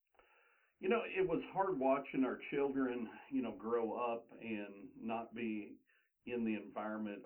Distortion from loudness and static
First, it has some static in the recording for some reason likely due to the usb mic. And this static seems to be worse when the loudness of the person talking kicks in. I was able to reduce the static sound but i still get quite a bit distortion when he is loud.
This was recorded with audacity over a blue yeti microphone on Win7.
The man’s voice was quite booming and It sounds like the levels were just too loud for the mic?